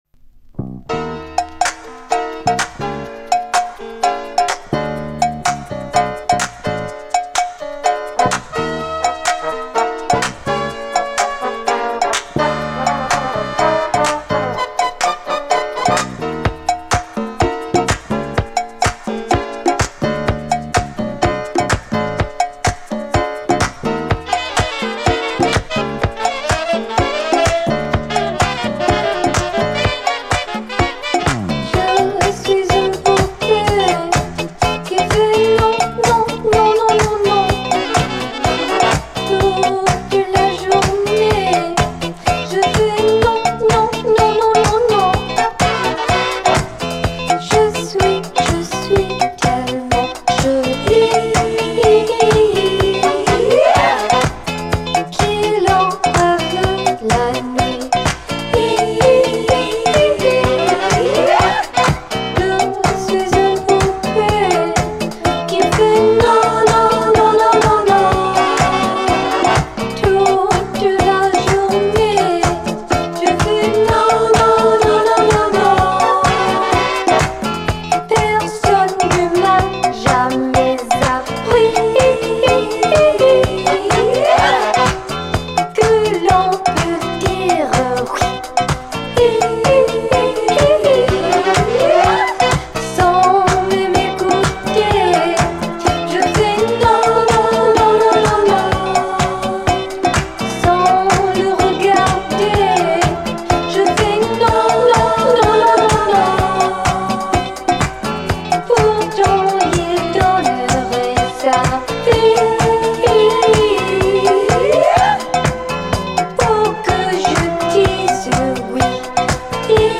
Filed under disco